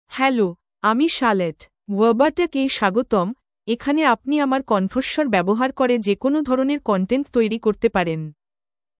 FemaleBengali (India)
CharlotteFemale Bengali AI voice
Charlotte is a female AI voice for Bengali (India).
Voice sample
Listen to Charlotte's female Bengali voice.